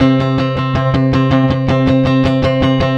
Track 16 - Guitar 01.wav